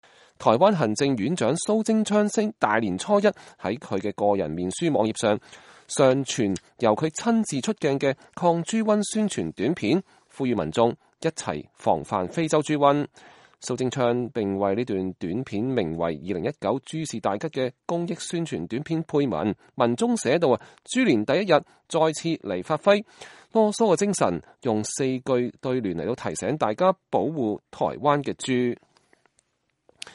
台灣行政院長蘇貞昌大年初一（2月5日）在他的個人臉書網頁上傳由他親自出鏡的抗豬瘟宣傳片，呼籲民眾一同防範非洲豬瘟。